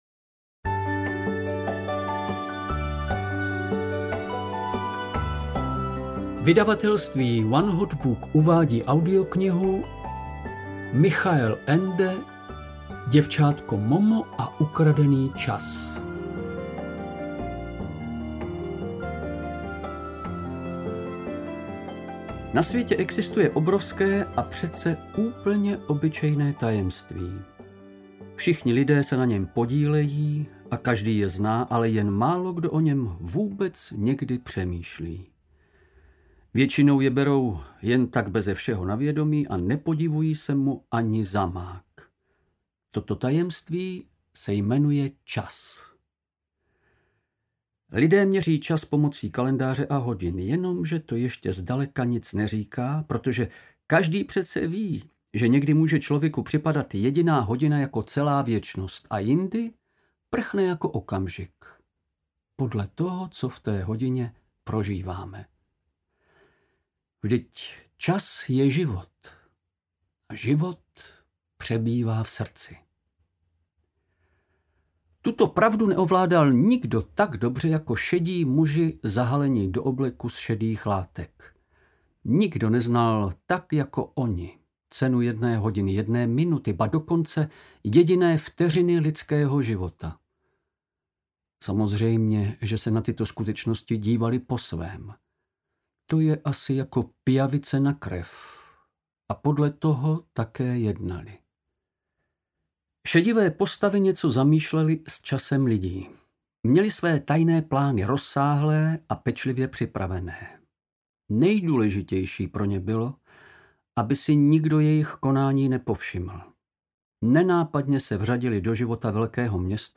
Děvčátko Momo a ukradený čas audiokniha